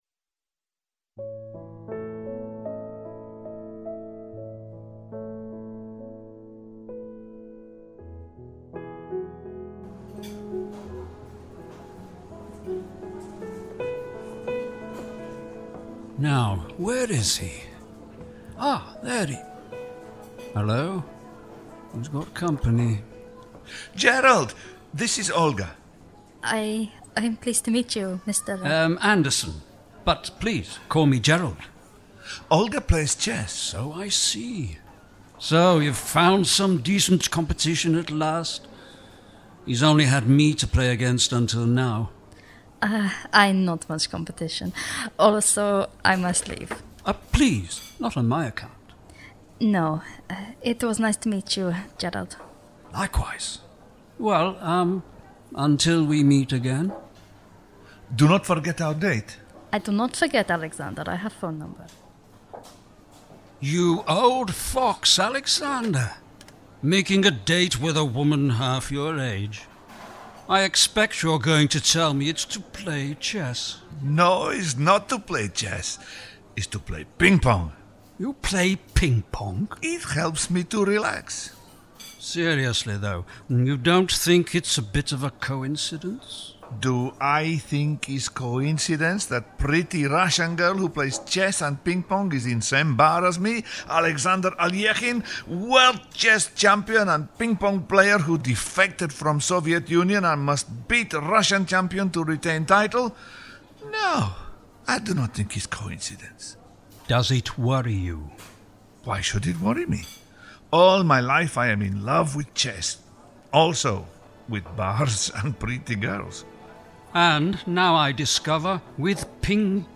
A new audio-drama coming soon from the award winning 'Cornucopia Radio'.